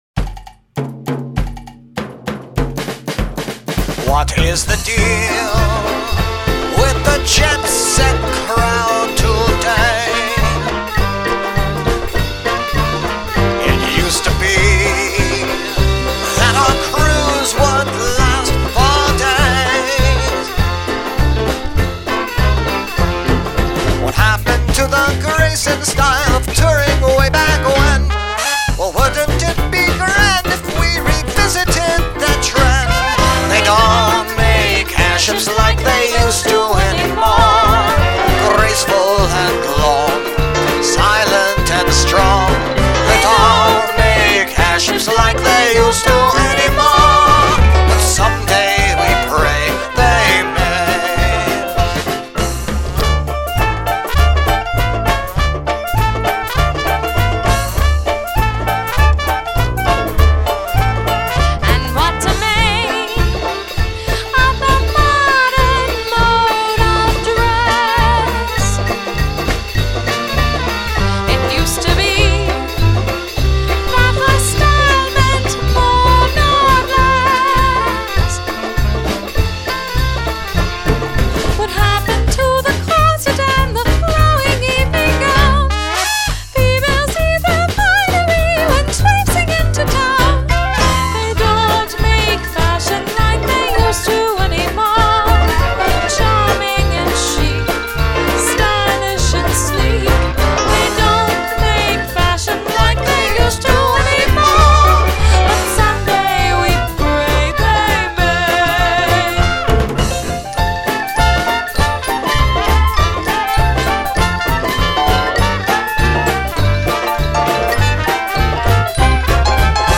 Click to listen to the lofty strains of our original,